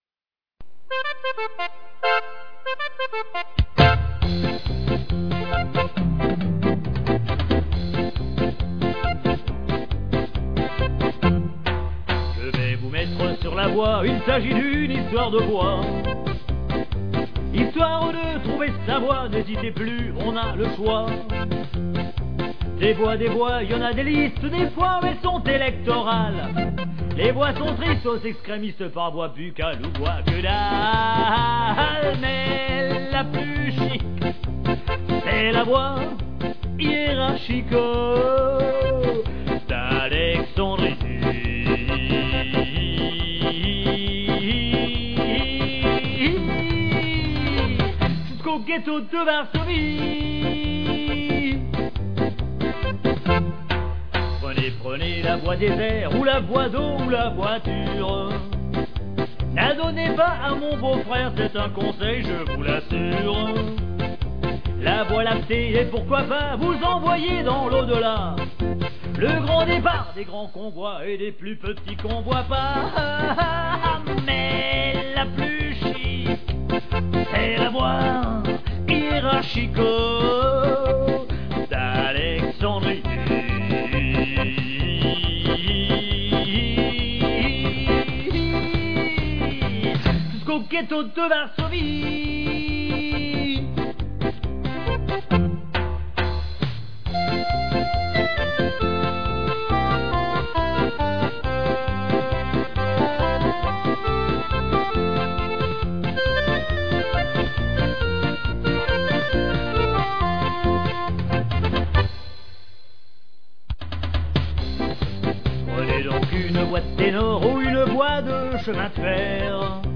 écouter la version ordinateur